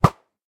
bow.ogg